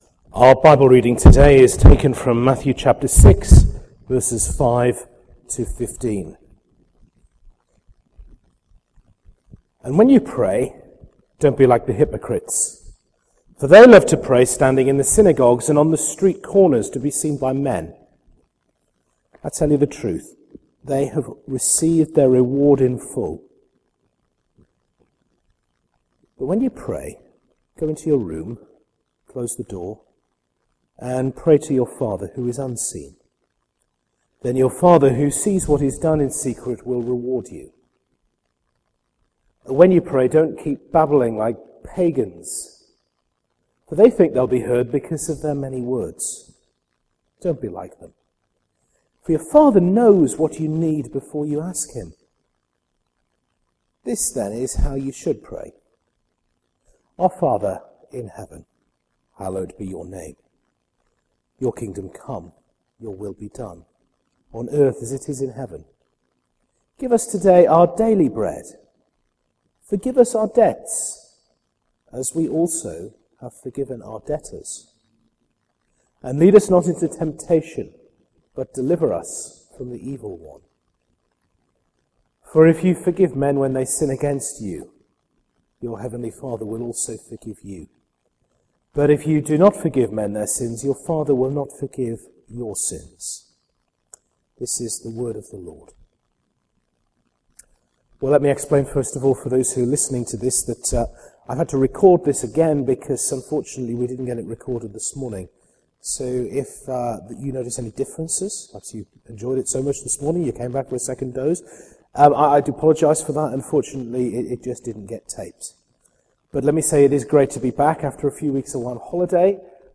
Centenary 2011 sermons – Matthew 6v5 to 15